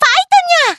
match-ready.wav